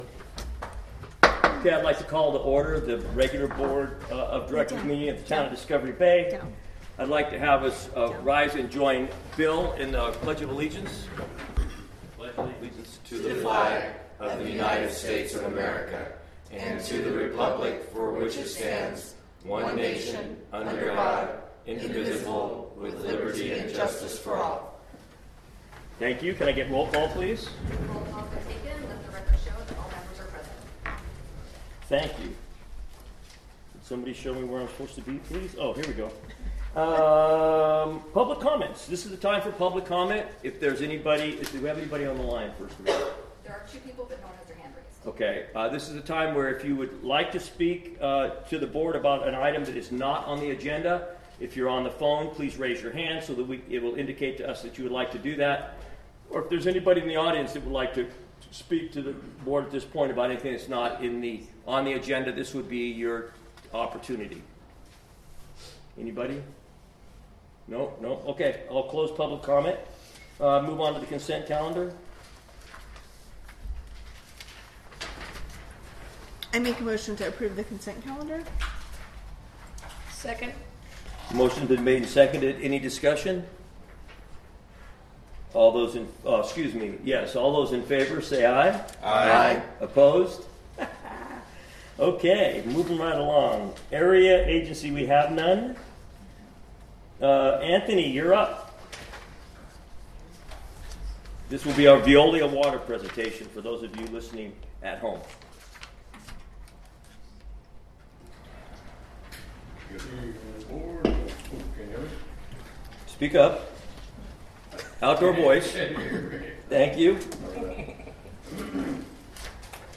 The Town of Discovery Bay CSD meets twice monthly on the first and third Wednesday of each month at 7:00 p.m. at the Community Center located at 1601…
Board of Directors Meeting